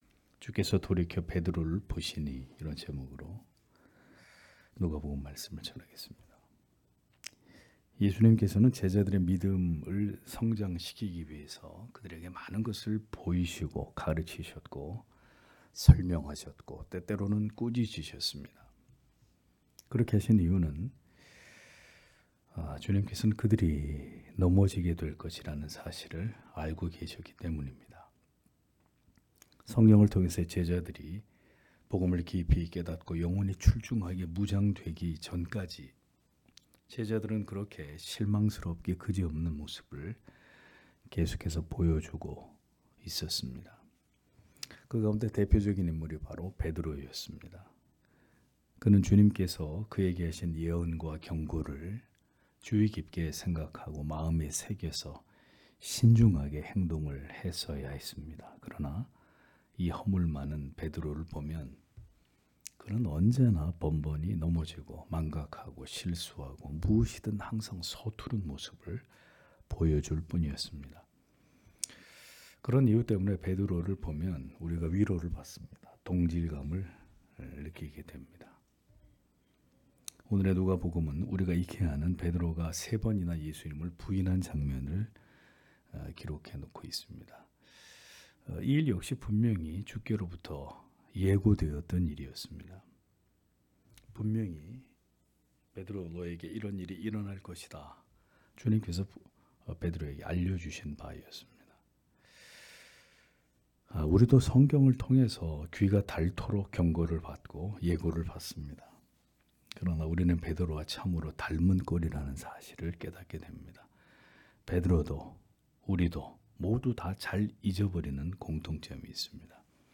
금요기도회 - [누가복음 강해 173] '주께서 돌이켜 베드로를 보시니' (눅 22장 54- 62절)